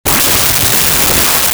Sweep
sweep.wav